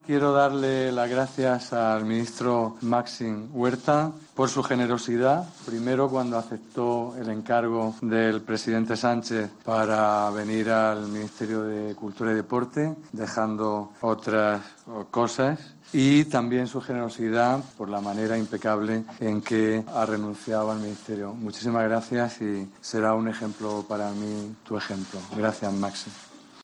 Como en la toma de posesión del resto de miembros del gabinete de Pedro Sánchez, el nuevo ministro de Cultura y Deporte ha prometido su cargo ante el Rey, en una breve ceremonia y sin símbolos religiosos.